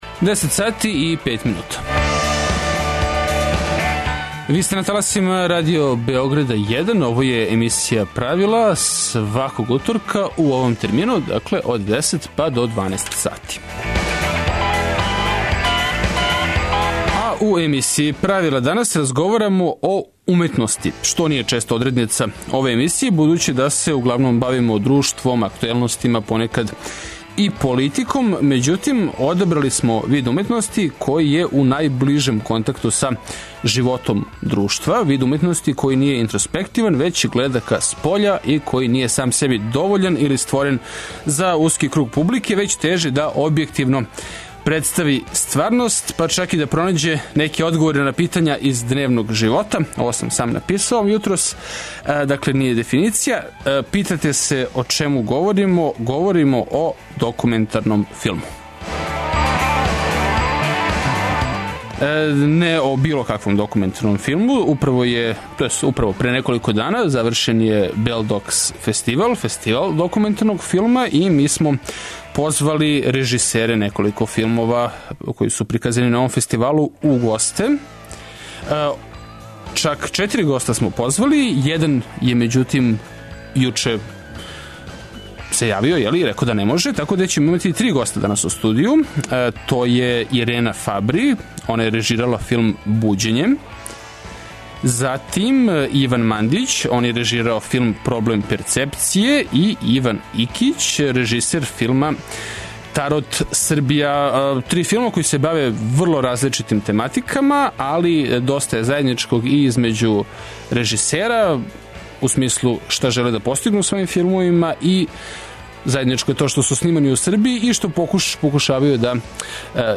У емисији Правила гости су режисери три филма које смо могли видети на фестивалу